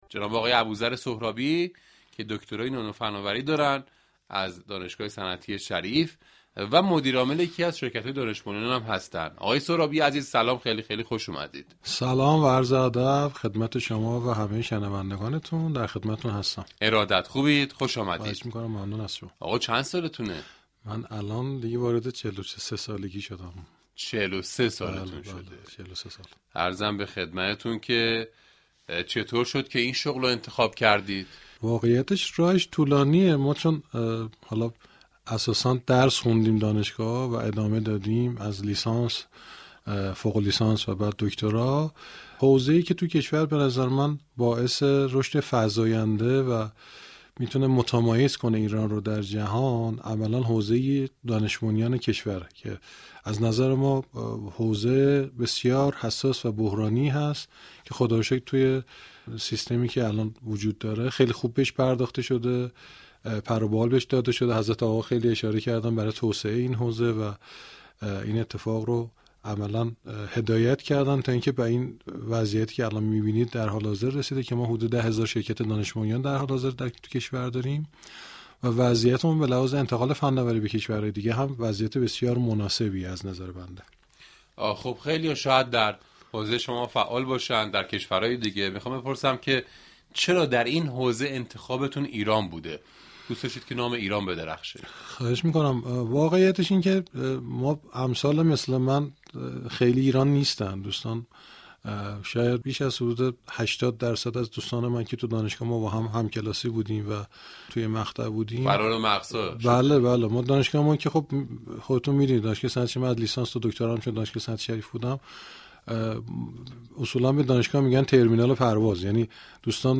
یكی از نخبگان كشور در گفتگو با رادیو صبا ولایت مداری را از مهم‌ترین ویژگی‌های رئیس جمهور منتخب دانست